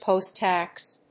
アクセントpòst‐táx